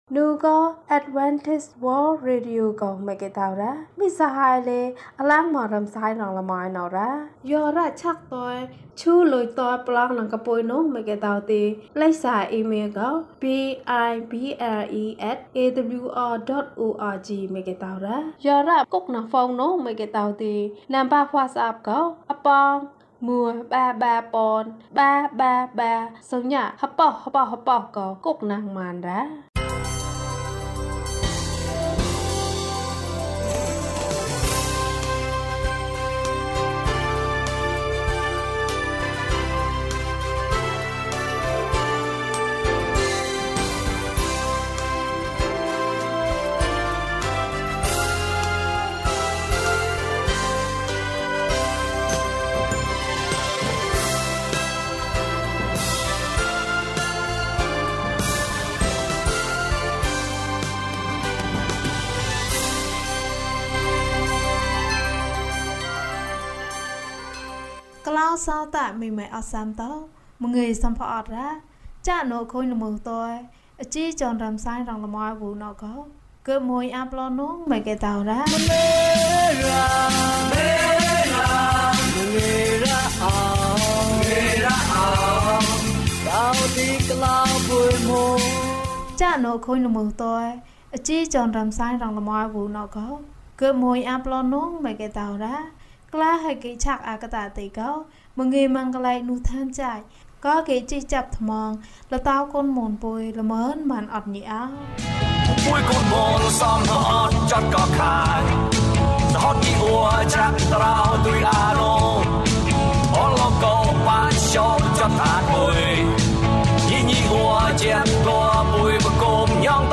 ခရစ်တော်နှင့်အတူ လျှောက်လှမ်းနေပါတယ်။ ကျန်းမာခြင်းအကြောင်းအရာ။ ဓမ္မသီချင်း။ တရားဒေသနာ။